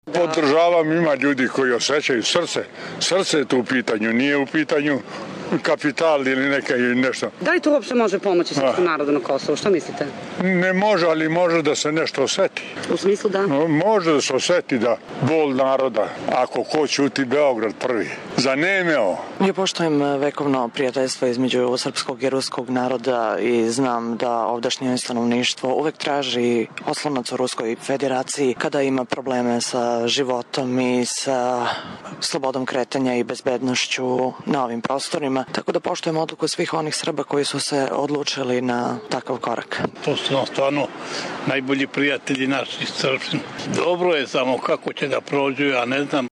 Anketa u Mitrovici o ruskom državljanstvu